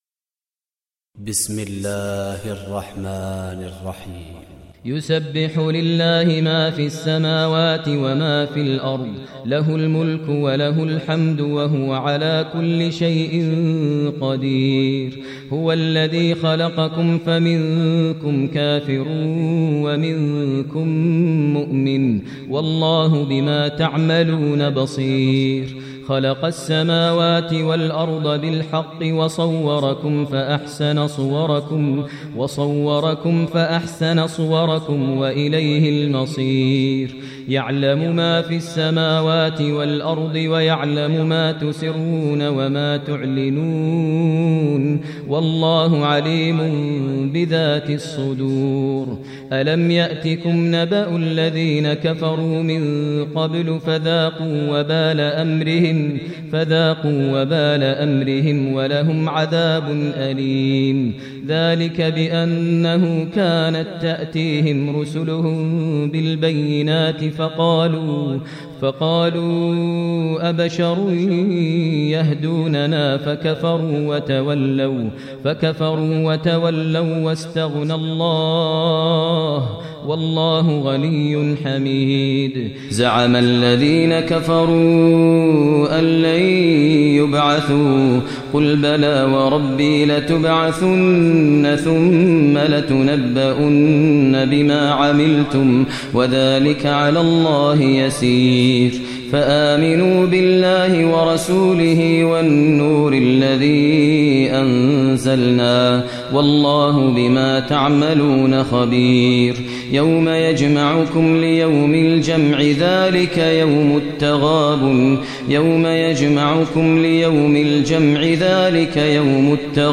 Surah Taghabun Recitation by Sheikh Maher Mueaqly
Surah Taghabun, listen online mp3 tilawat / recitation in Arabic recited by Imam e Kaaba Sheikh Maher al Mueaqly.